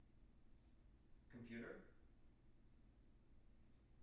wake-word
tng-computer-213.wav